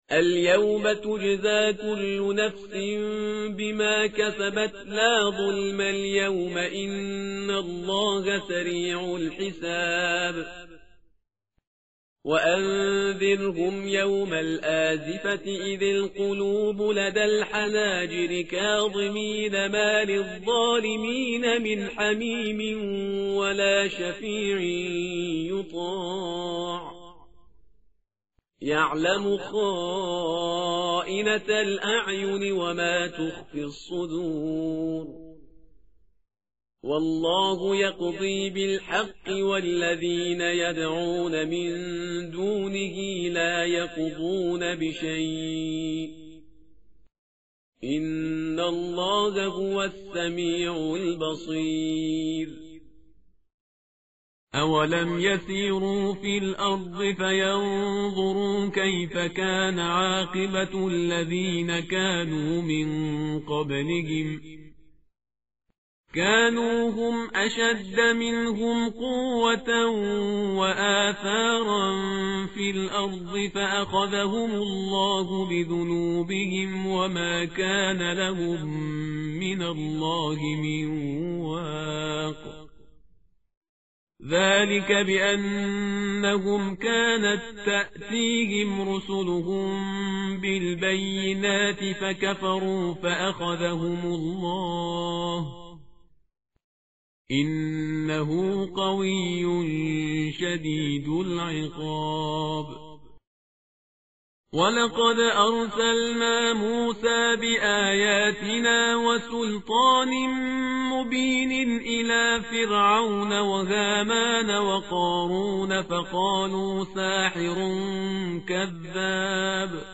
متن قرآن همراه باتلاوت قرآن و ترجمه
tartil_parhizgar_page_469.mp3